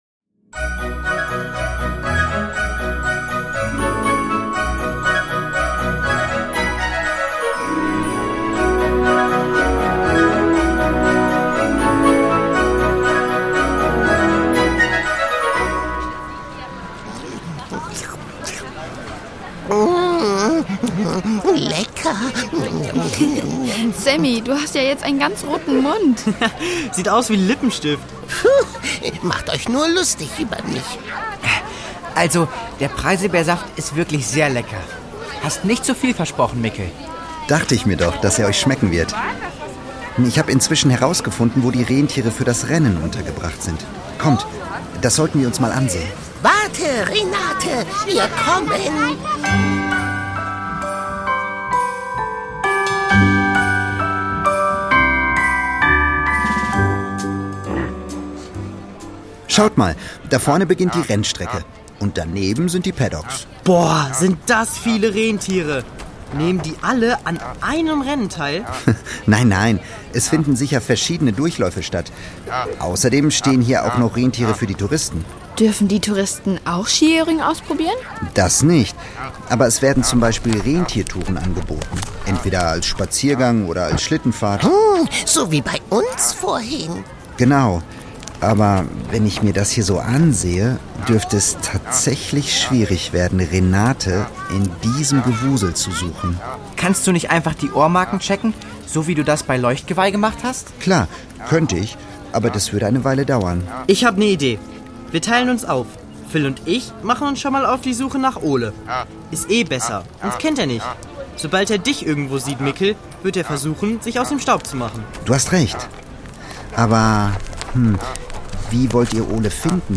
Die Doppeldecker Crew | Hörspiel für Kinder (Hörbuch)